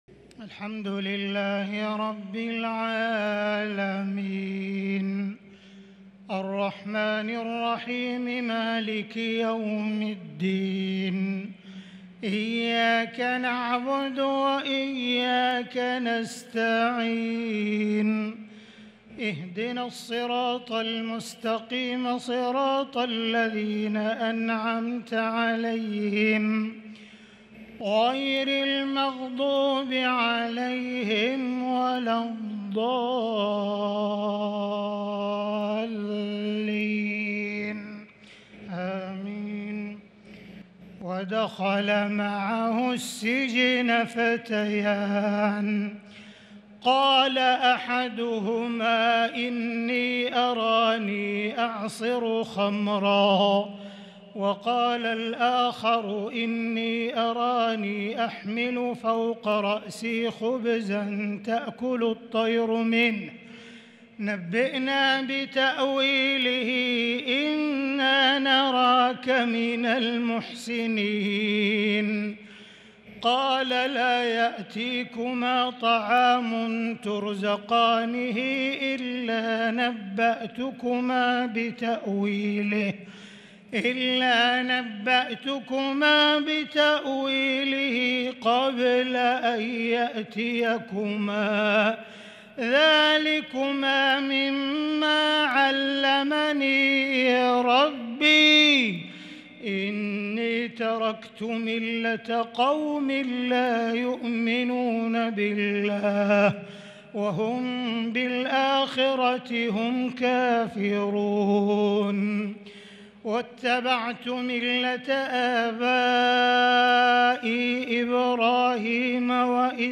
تراويح ليلة 16 رمضان 1443هـ من سورة يوسف {36-53} Taraweeh 16 st night Ramadan 1443H Surah Yusuf > تراويح الحرم المكي عام 1443 🕋 > التراويح - تلاوات الحرمين